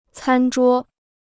餐桌 (cān zhuō): dining table; dinner table.